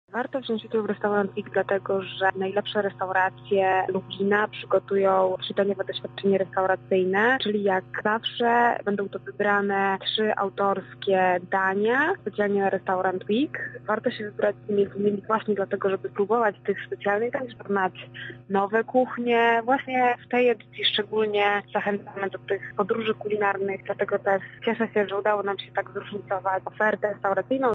O tym co nowego spotka lubelskich smakoszy mówi